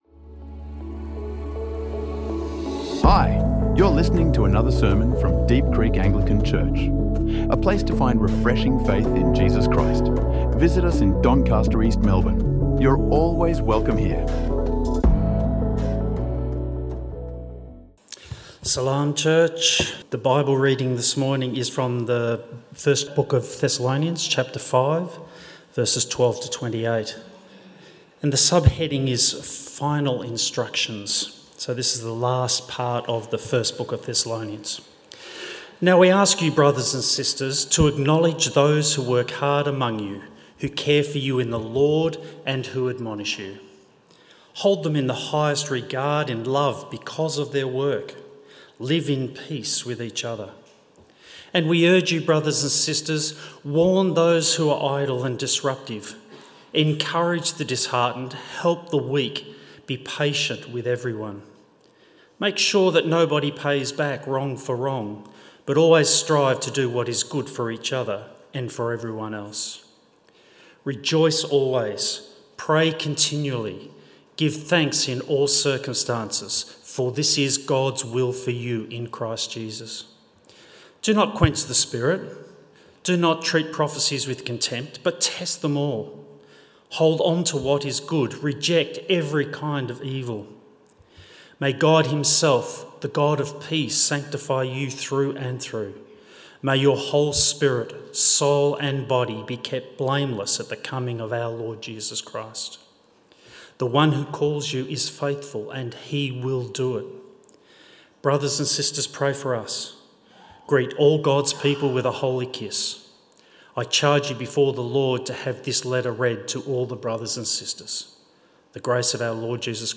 The Glue That Sticks Us Together | Sermons | Deep Creek Anglican Church